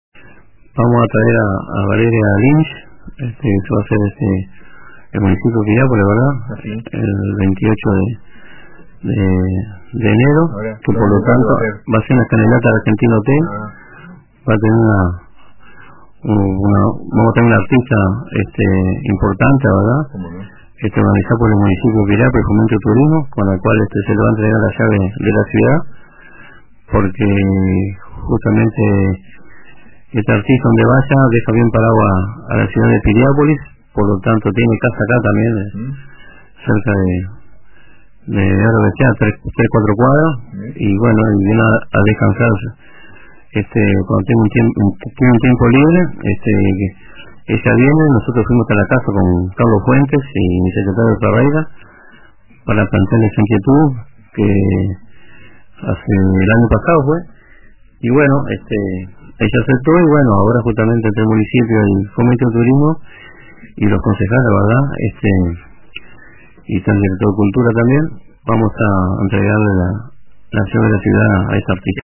El alcalde del Municipio de Piriápolis, René Graña, señaló en declaraciones al programa Radio Con Todos de RBC que la artista recibirá las llaves de la ciudad durante su visita.